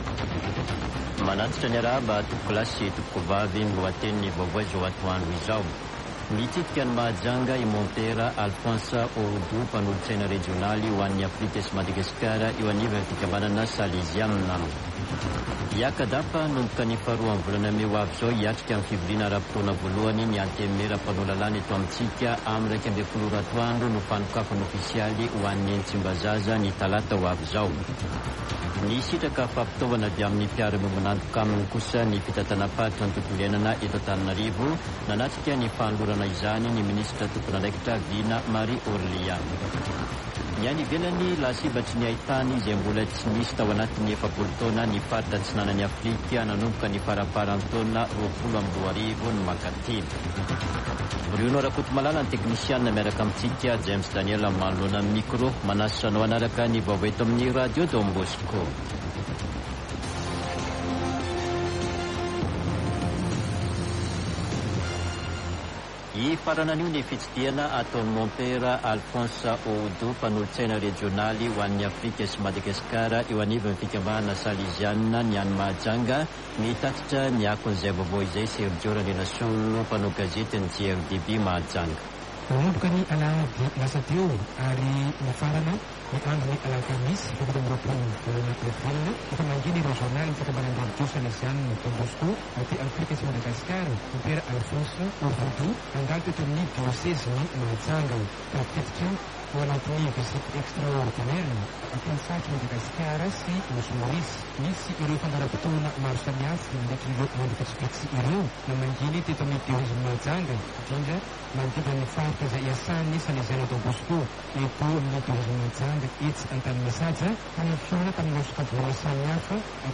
[Vaovao antoandro] Alakamisy 27 avrily 2023